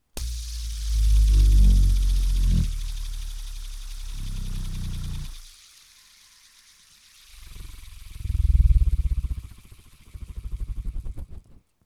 • spinner close up - moving air and mic.wav
Recorded in a small cabinet with a Tascam Dr 40. Can also help with windy bass sounds.
spinner_close_up_-_moving_air_and_mic_Fs0.wav